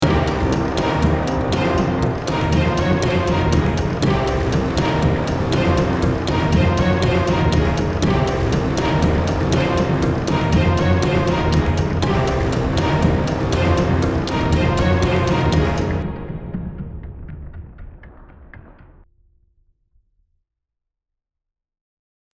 Alternate pattern with original drum instruments.
As you can hear it gives a totally different feel and you can use this technique to spark your inspiration when you’re feeling a bit creatively dry!